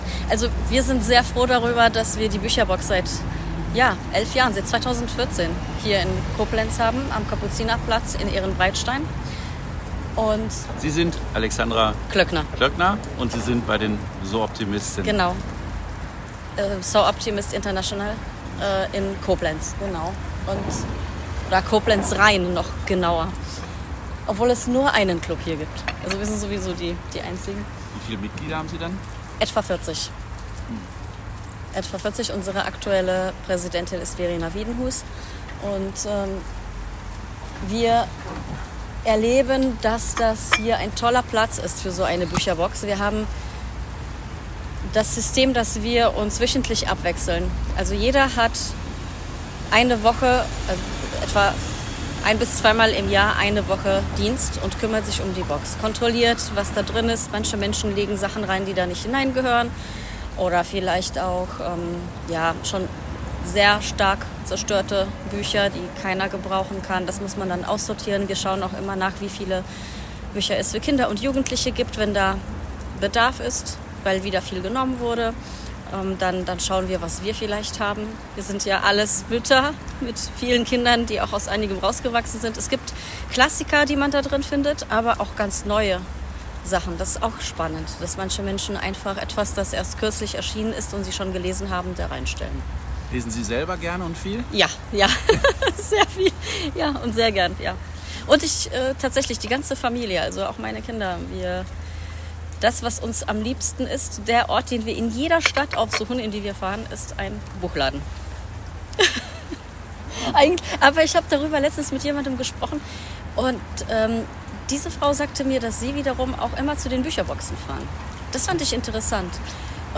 016-Kapuzinerplatz-Koblenz.mp3